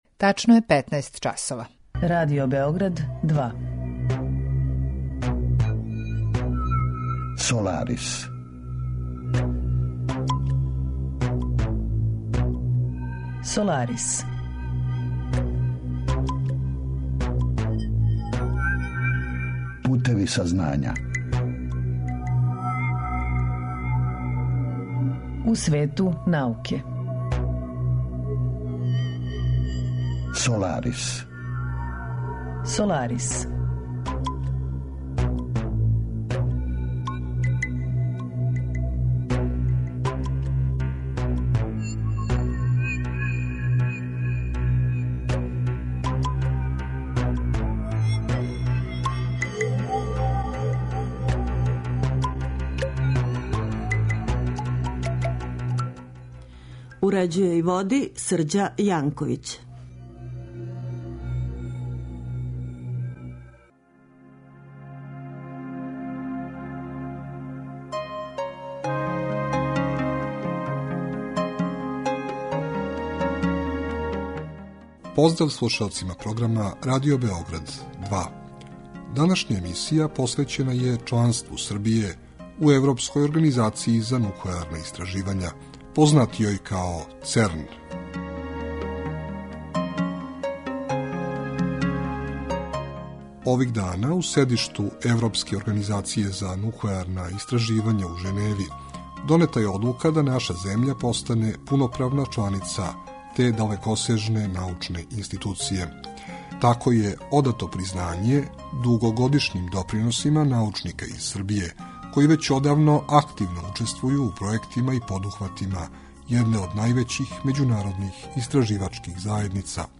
Саговорница: научна саветница